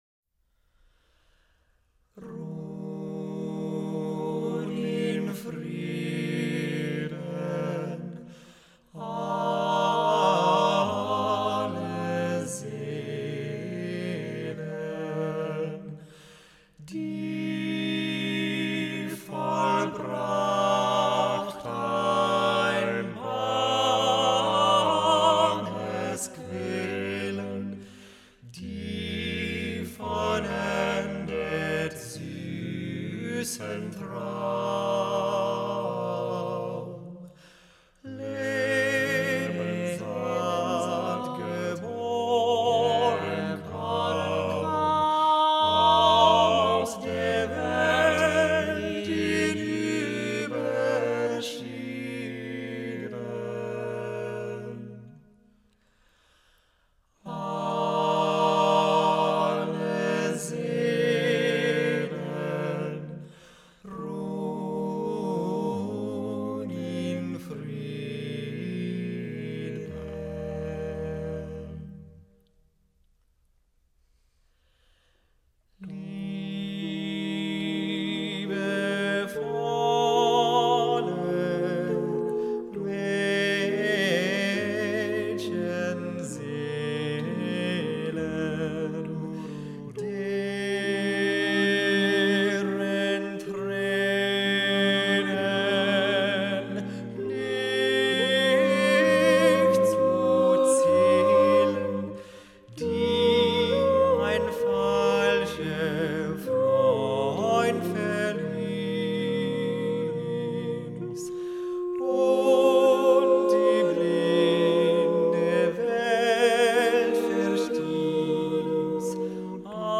SATB voices, a cappella